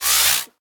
Sfx_tool_hoverpad_rotate_start_01.ogg